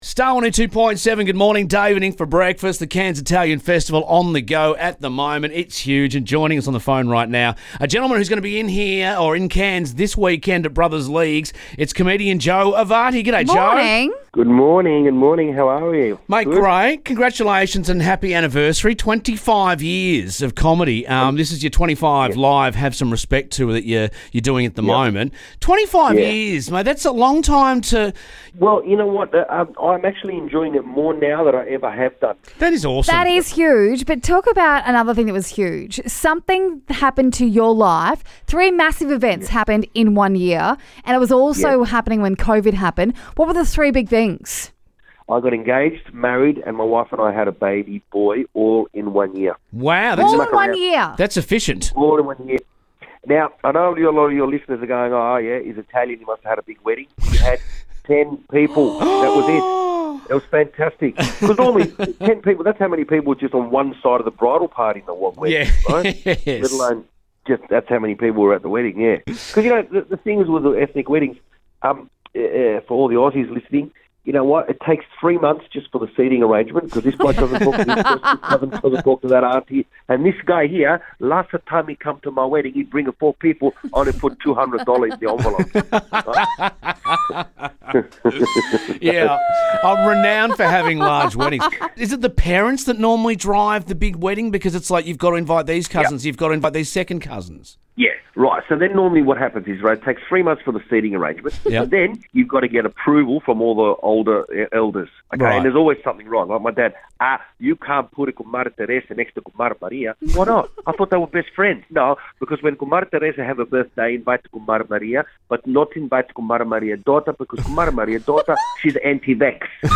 We chat to comedian Joe Avati who's in town for his gig at Brothers this weekend as part of the Cairns Italian Festival.